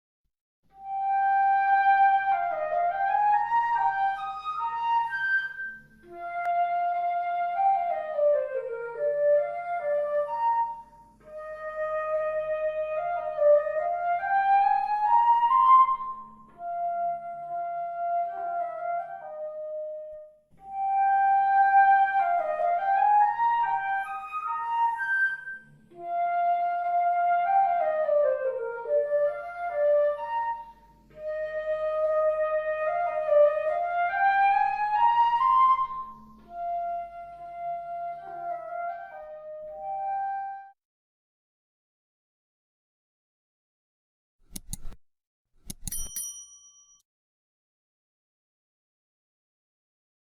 Sonido+De+Flauta+Travesera (audio/mpeg)
FLAUTA TRAVESERA familia: viento madera